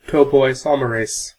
Ääntäminen
RP : IPA : /ˌtɒpəʊˌaɪˈsɒməˌɹeɪs/ GA : IPA : /ˌtɑpoʊˌaɪˈsɑməˌɹeɪs/